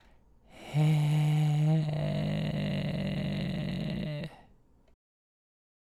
次は、喉頭を上げながらパーの声で「え」か「あ」と発声し、息が無くなるまで出していると、緩いじりじり音が出てきます。
※見本音声(「え」の音)